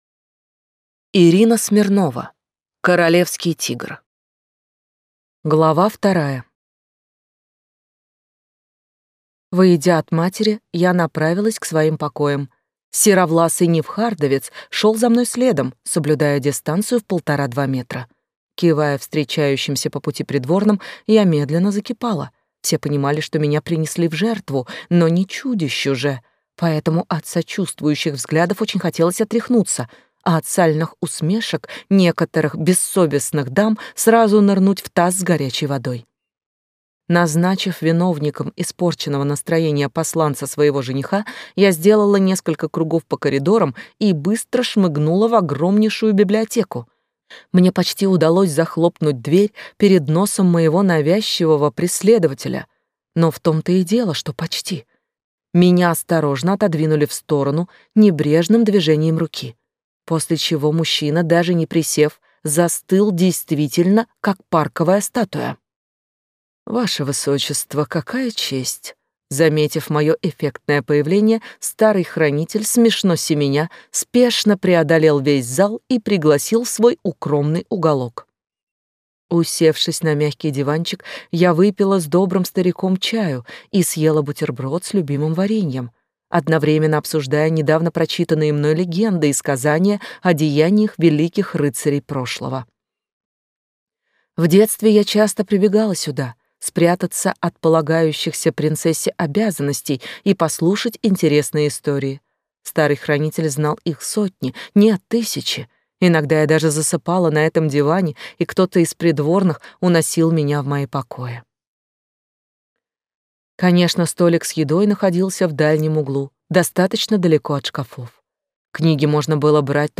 Аудиокнига Королевский тигр | Библиотека аудиокниг
Прослушать и бесплатно скачать фрагмент аудиокниги